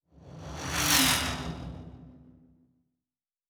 pgs/Assets/Audio/Sci-Fi Sounds/Movement/Fly By 07_5.wav at master
Fly By 07_5.wav